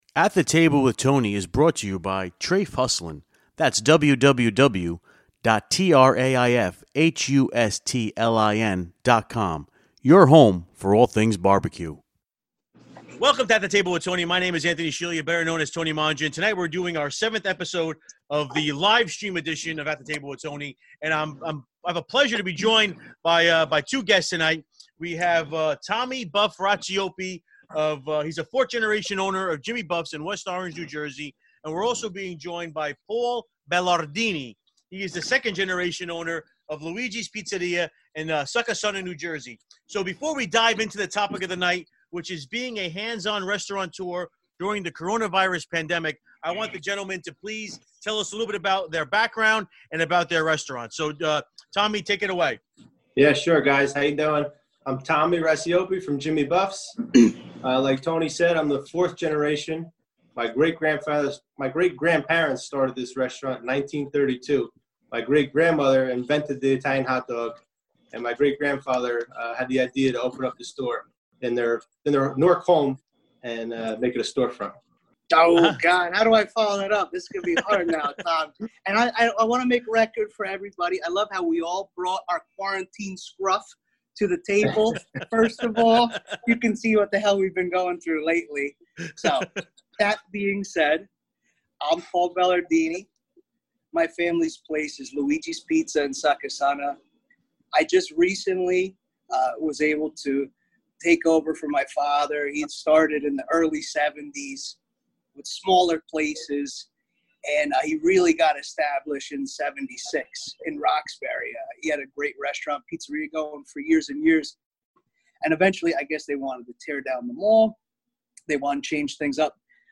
Livestream Show Episode 7: Being a Hands-On Restaurateur During the Coronavirus Pandemic